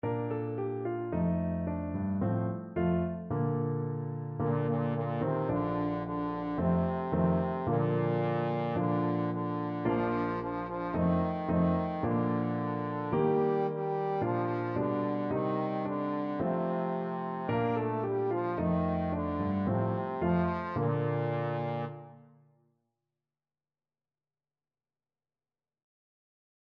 Traditional Music of unknown author.
Fast =c.110
2/4 (View more 2/4 Music)
Bb3-Bb4